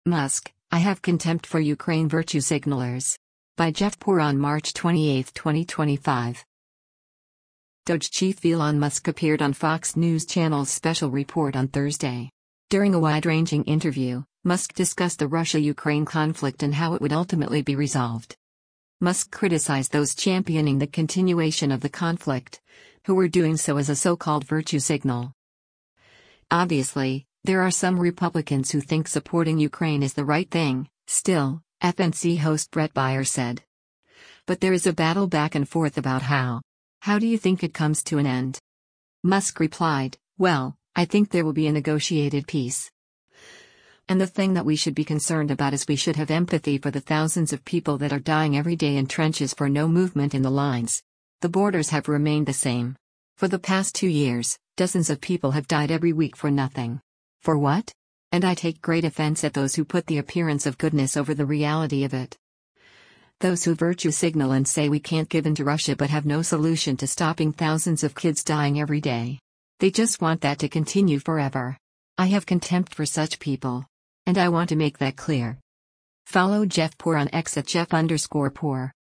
DOGE chief Elon Musk appeared on Fox News Channel’s “Special Report” on Thursday. During a wide-ranging interview, Musk discussed the Russia-Ukraine conflict and how it would ultimately be resolved.